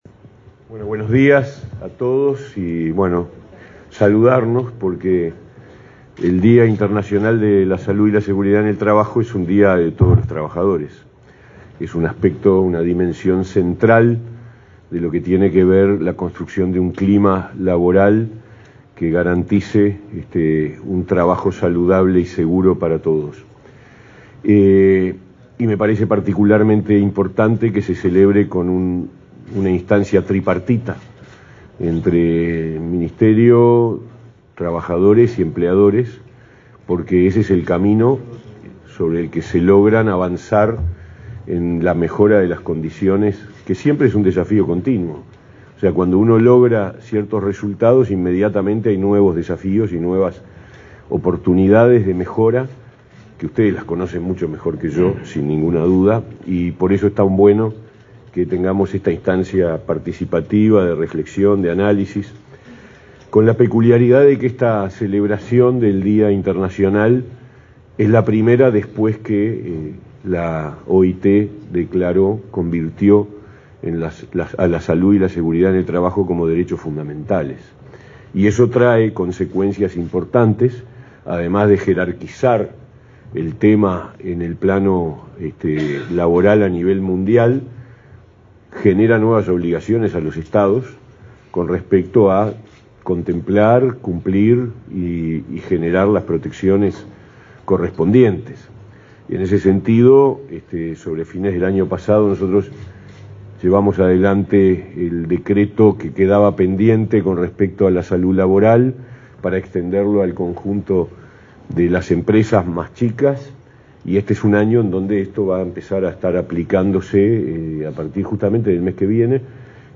Palabras del ministro de Trabajo, Pablo Mieres
El titular del Ministerio de Trabajo, Pablo Mieres, participó en el acto realizado en esa cartera por el Día Mundial de la Seguridad y la Salud en el